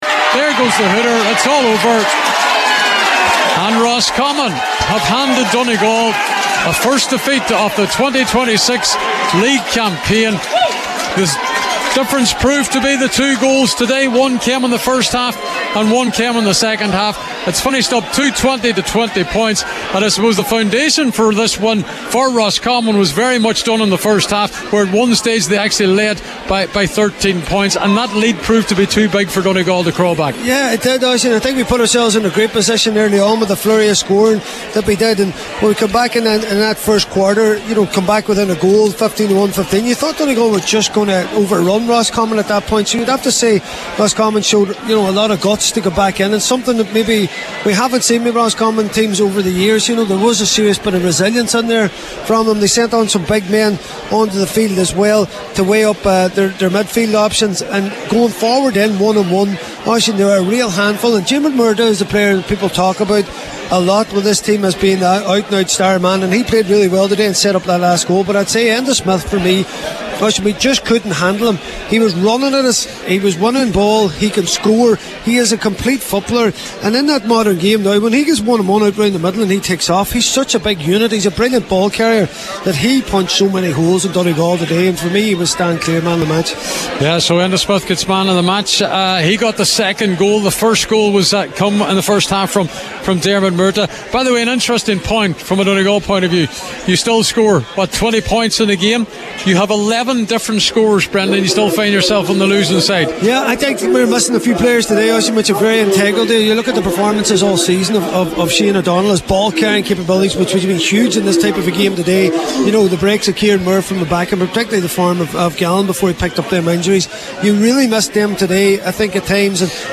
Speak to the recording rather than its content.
live at full time